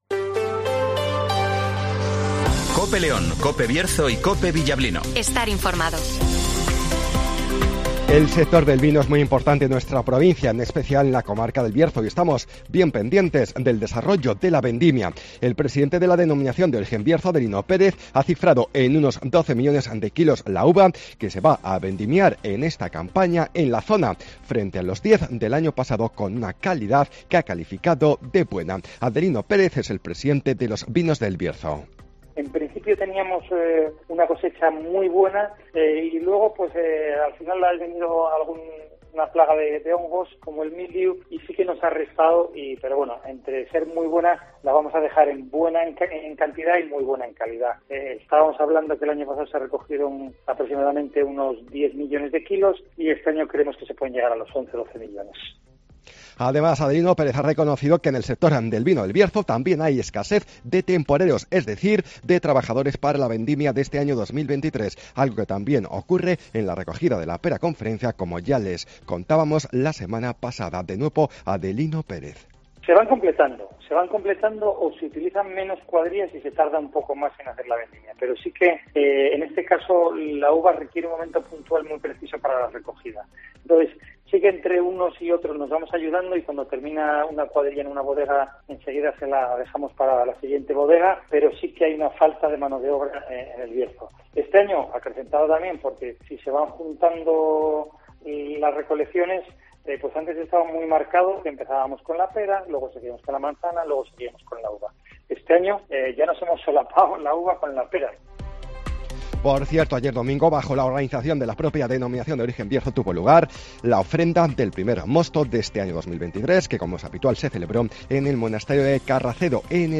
- Informativo Matinal 08:24 h